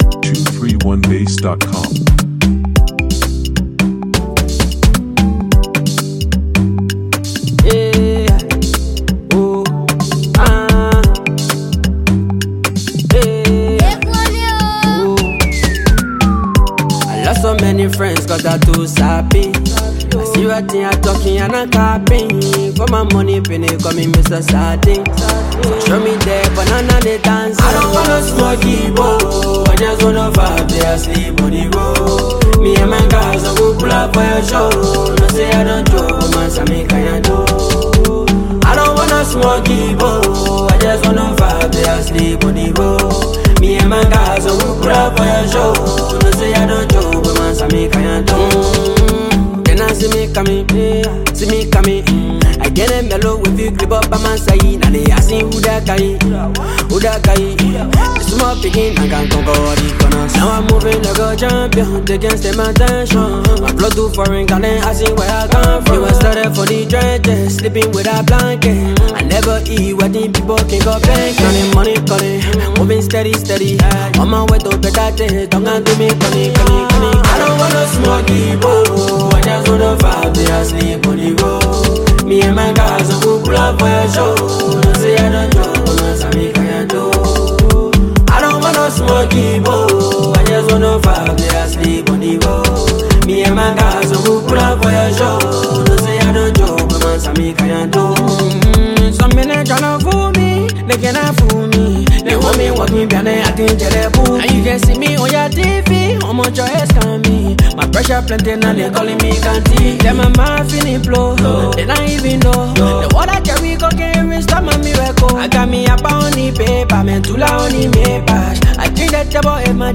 with a cool, laid-back vibe